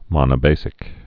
(mŏnə-bāsĭk)